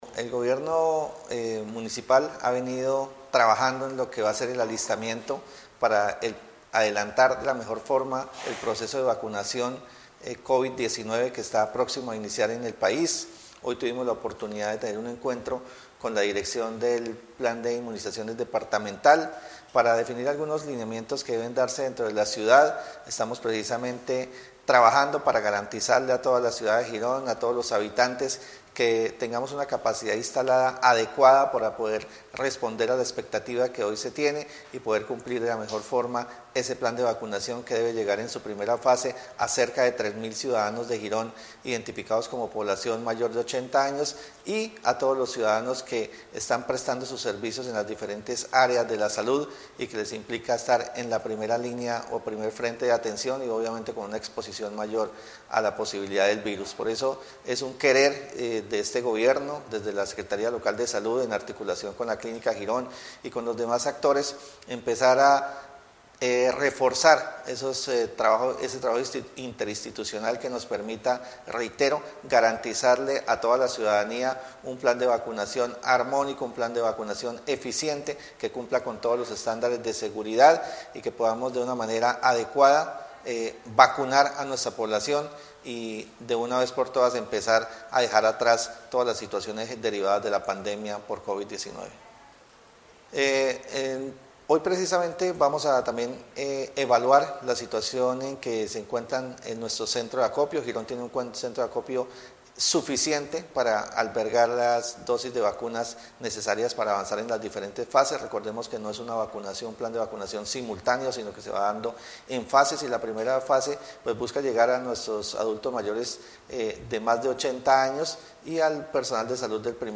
AUDIO SEC SALUD ENCARGADO.mp3